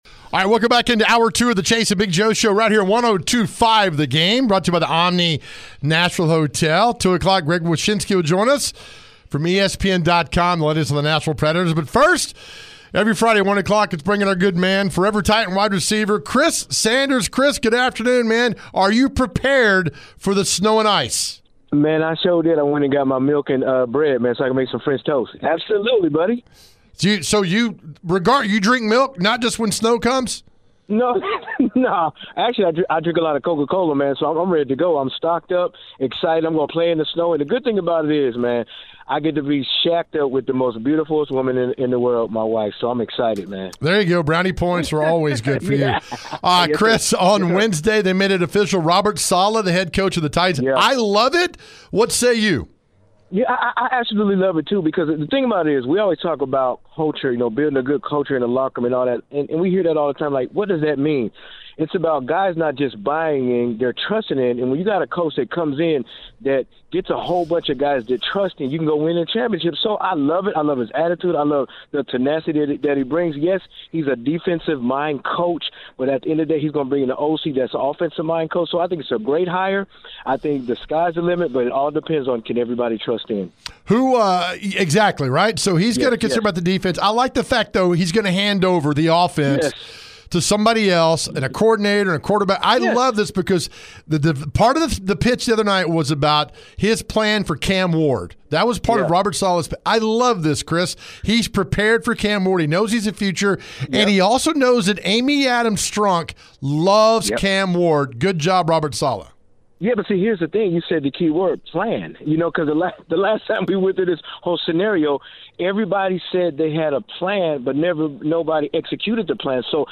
Forever Titans WR Chris Sanders joins the show to discuss new head coach Robert Saleh and who the team could be looking to hire as its offensive coordinator.